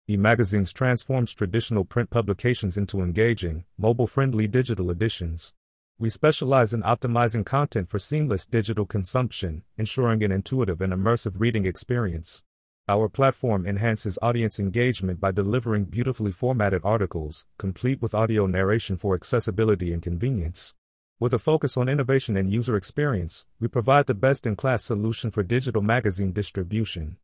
We turn text into lifelike speech by leveraging Neural Text-to-Speech systems to create natural-sounding human audio that keeps your subscribers engaged.